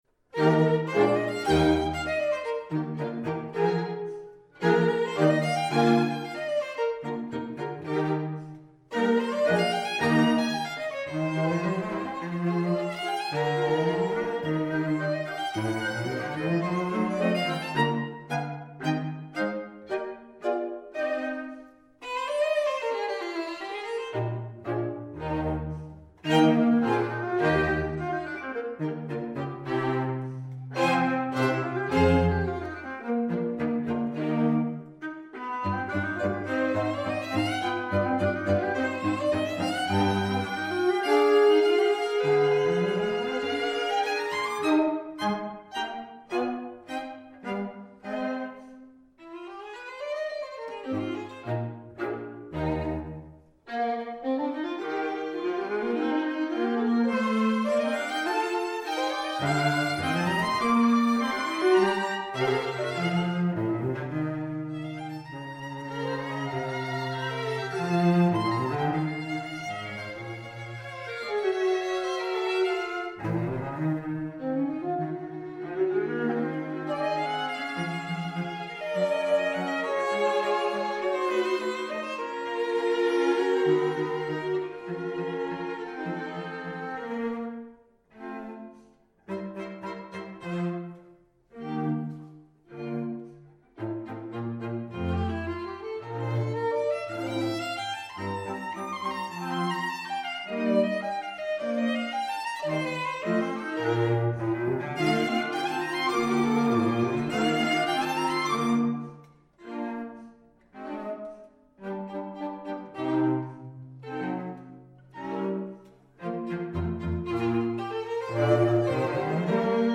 Soundbite 1st Movt
krommer-op24-2-movt1.mp3